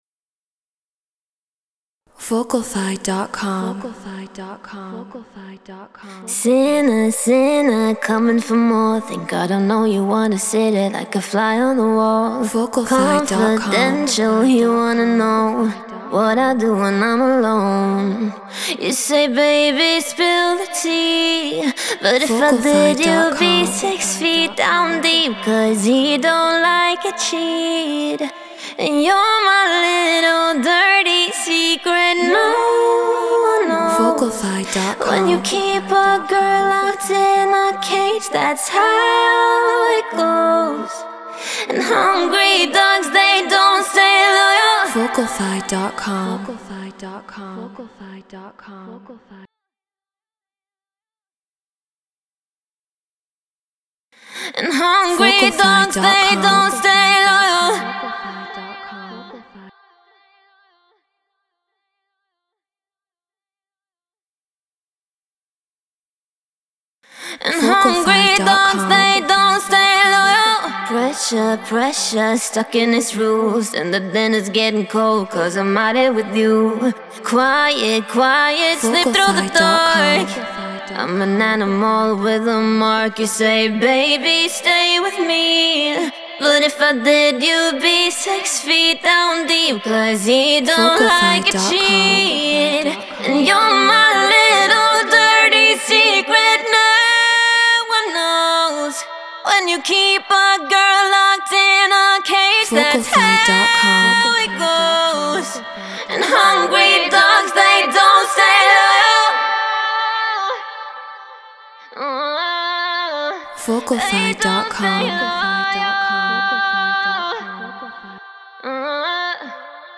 Future Trap 149 BPM Dmin
Shure SM7B
Treated Room